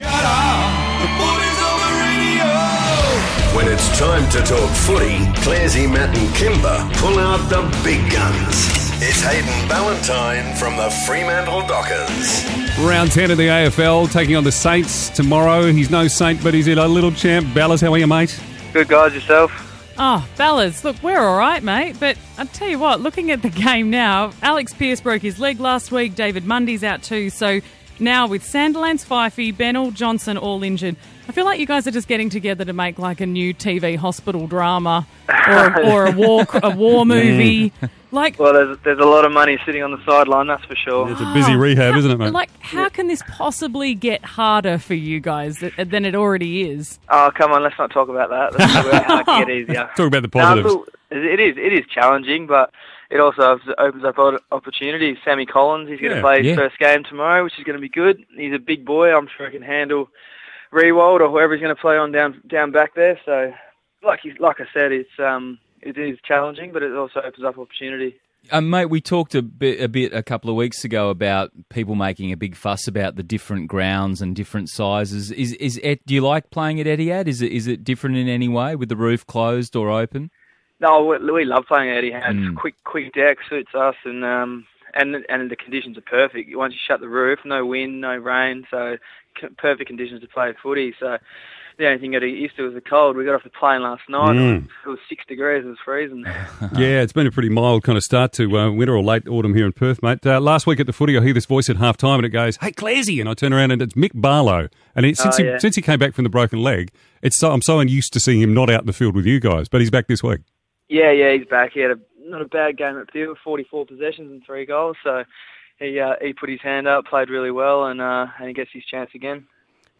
Hayden Ballantyne on Mix 94.5 - Friday 27 May